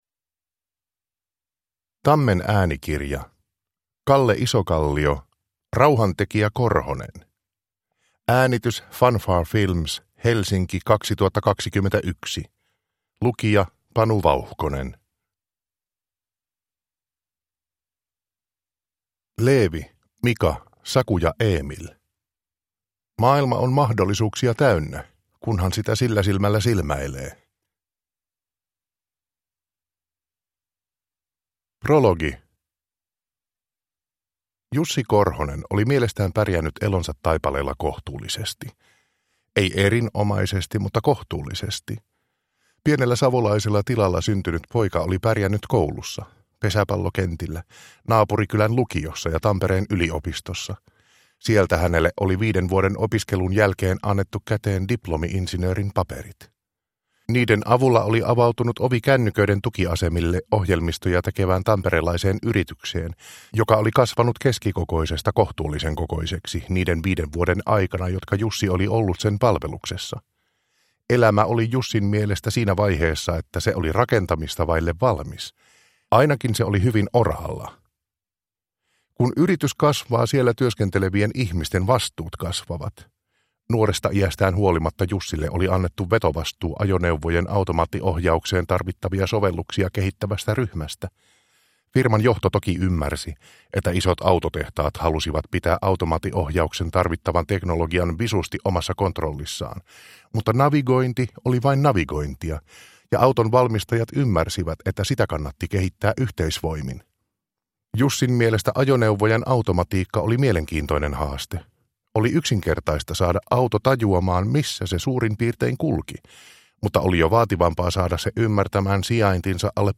Rauhantekijä Korhonen – Ljudbok